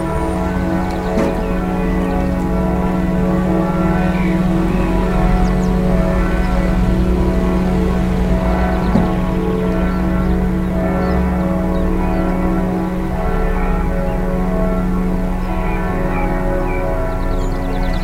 Description Ringing of Sevenum's church bells
Ringing of Sevenum's church bells